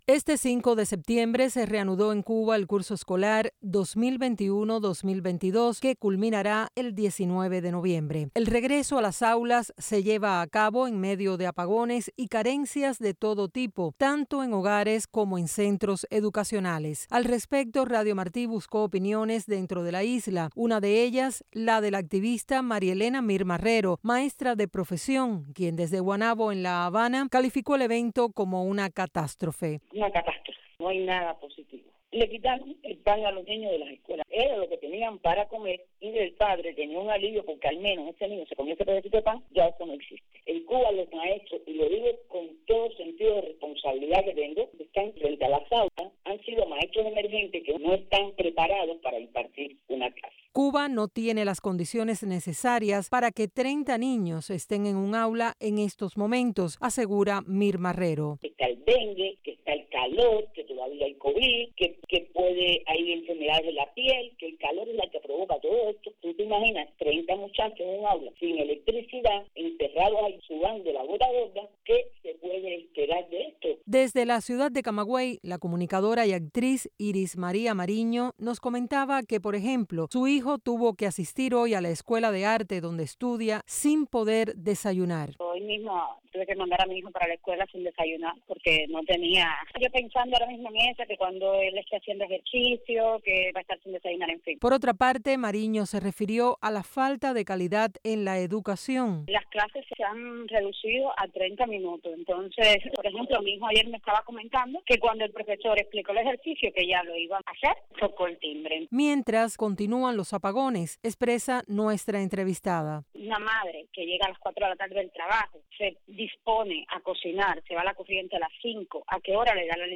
Madres opinan sobre el inicio de curso escolar 2021-2022